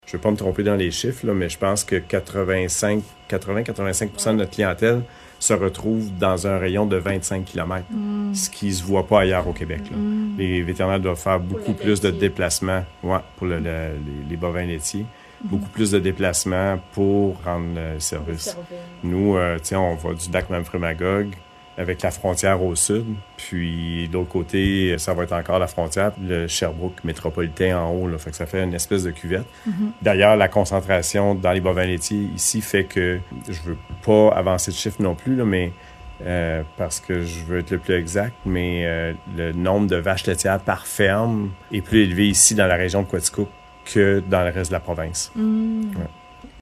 ENTREVUE-2.6.3-CLINIQUE-VET_01.mp3